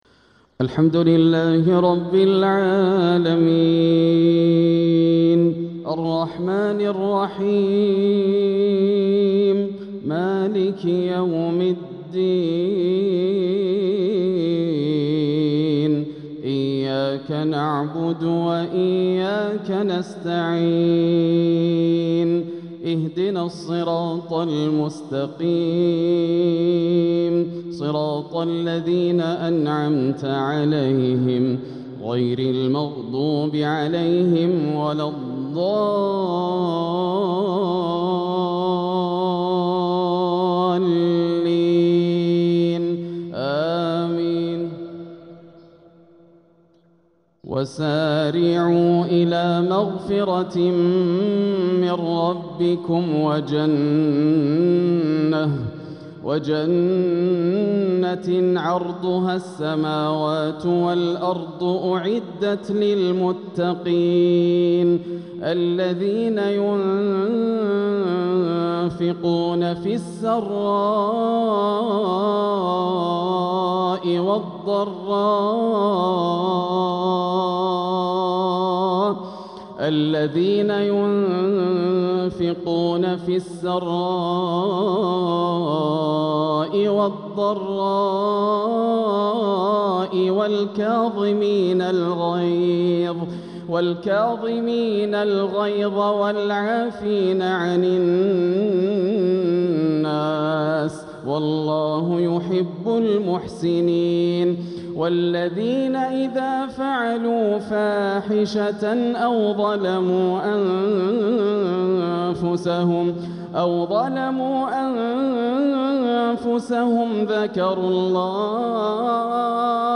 تلاوة من سورتي آل عمران والحديد | مغرب الأحد 2-6-1447هـ > عام 1447 > الفروض - تلاوات ياسر الدوسري